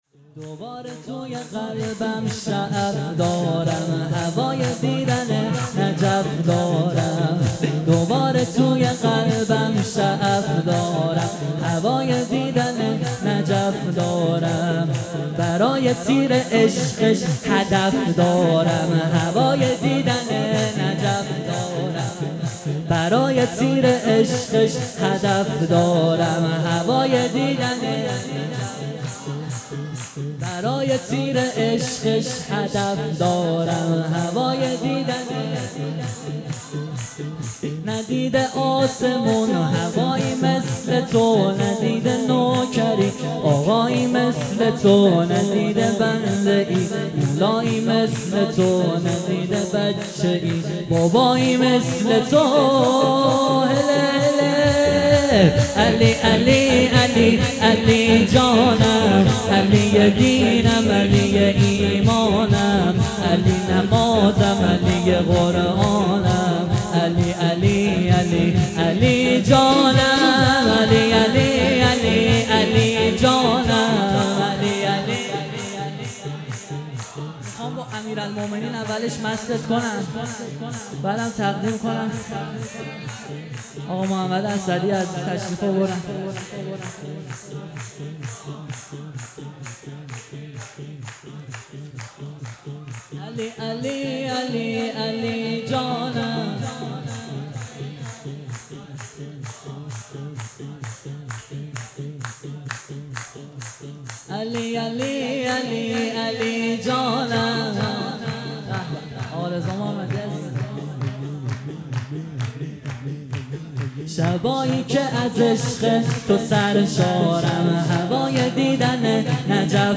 شور:دوباره هوای دیدن نجف دارم